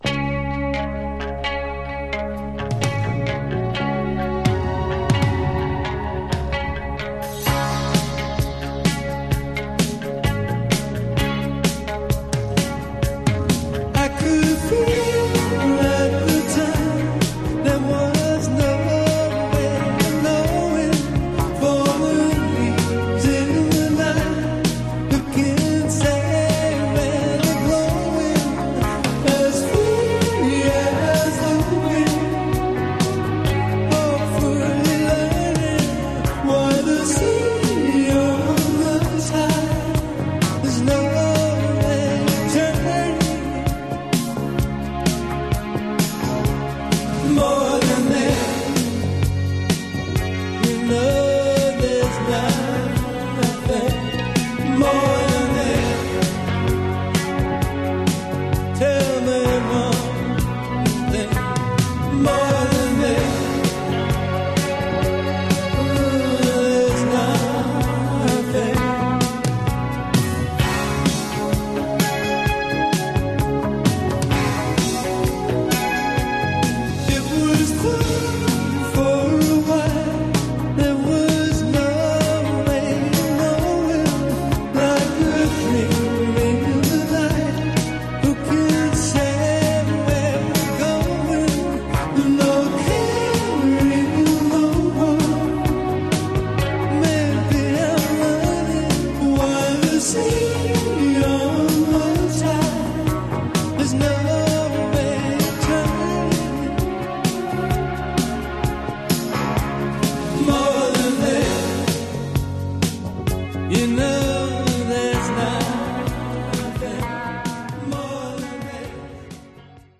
Genre: Techno/Synth Pop